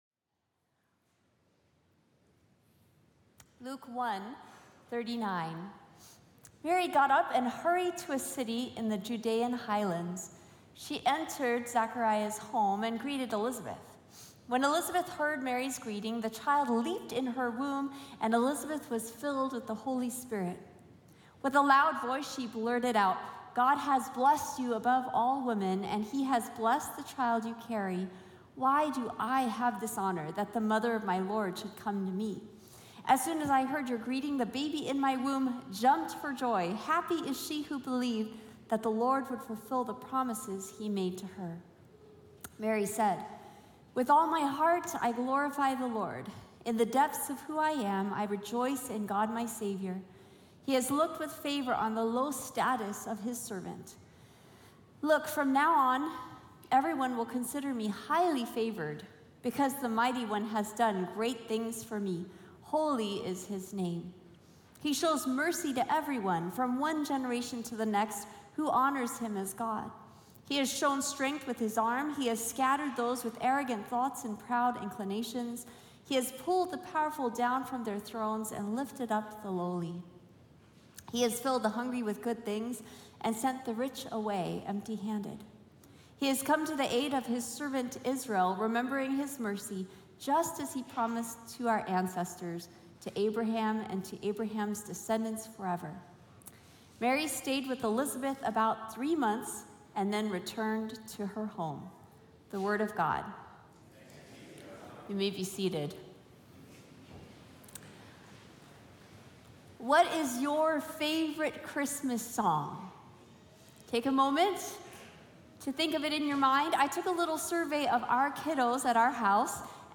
Sermon Archive | La Sierra University Church